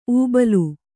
♪ ūbalu